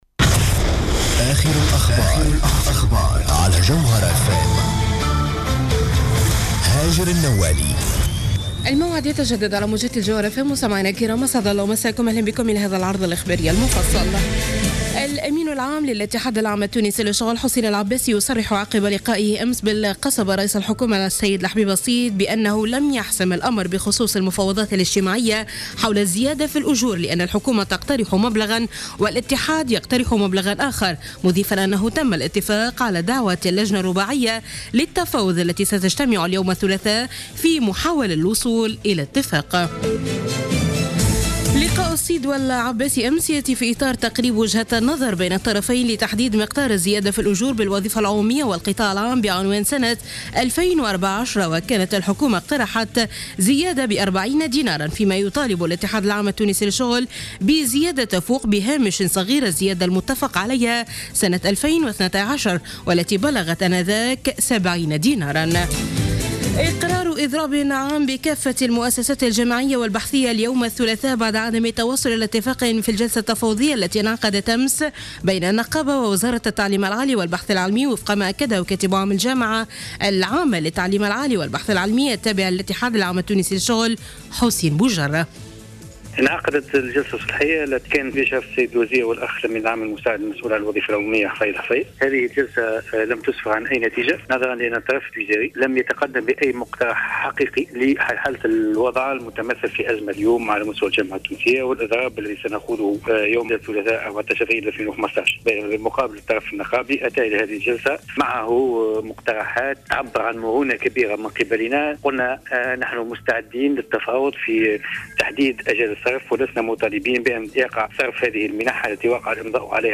نشرة أخبار منتصف الليل ليوم الثلاثاء 14 أفريل 2015